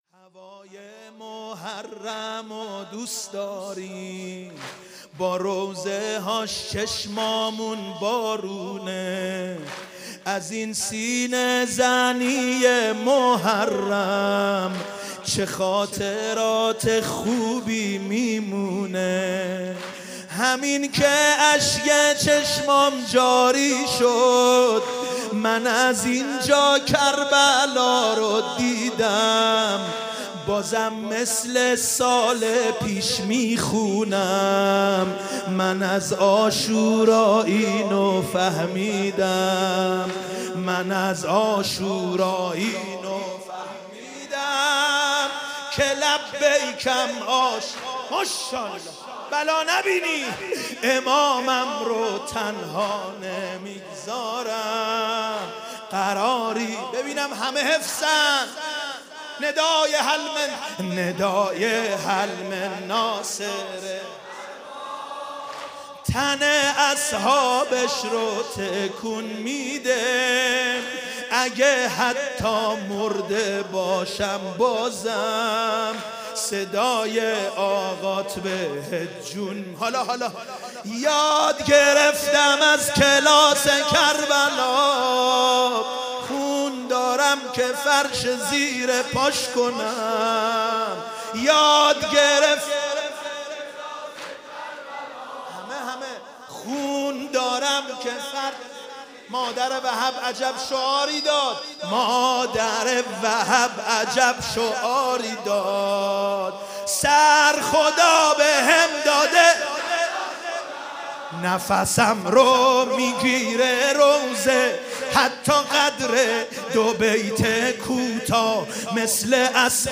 شب اول محرم - به نام نامی حضرت مسلم(ع)
محرم 95 | واحد | هوای محرم ودوست داریم